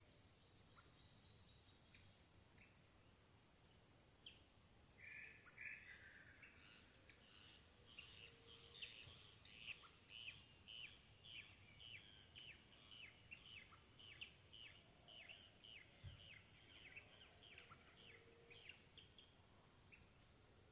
Cacholote Castaño (Pseudoseisura lophotes)
Fase de la vida: Adulto
Localización detallada: Reserva Pozo Del Arbolito
Condición: Silvestre
Certeza: Vocalización Grabada